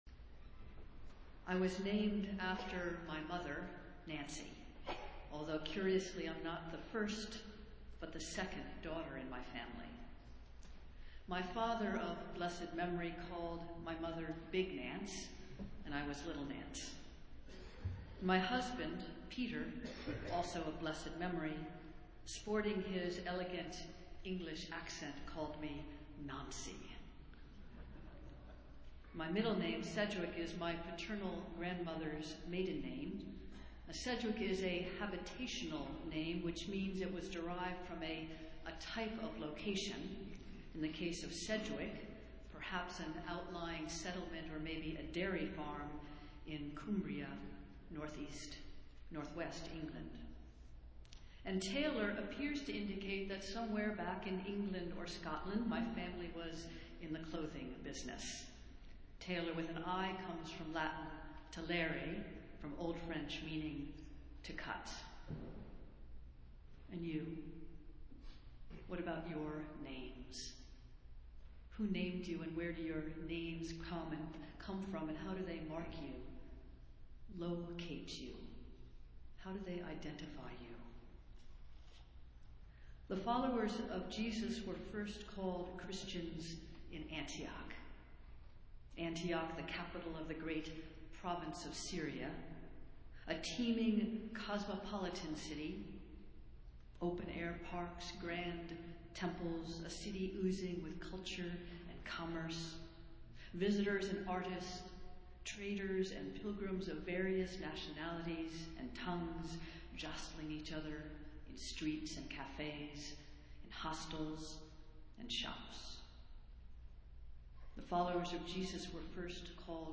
Festival Worship - Sixth Sunday after Epiphany